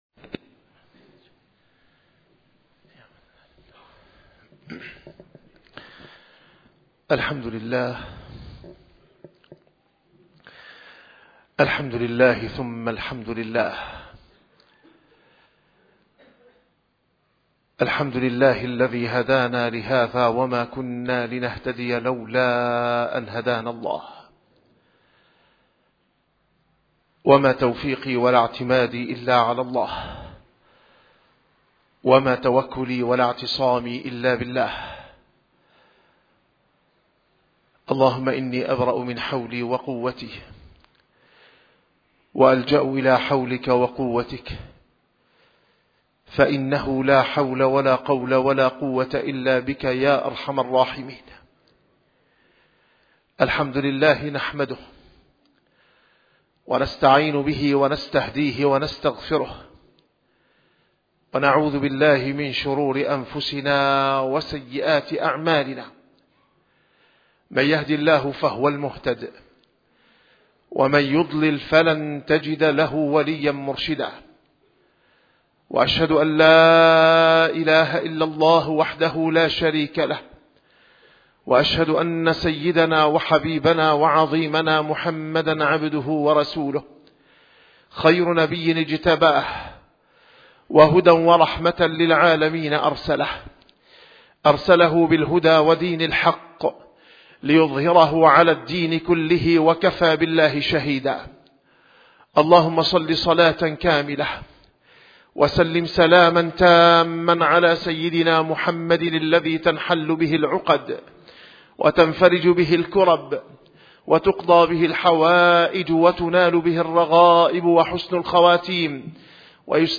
نسيم الشام › - الخطب - فضل العشر من ذي الحجة, والربط في الثواب بينها وبين أعمال الحج.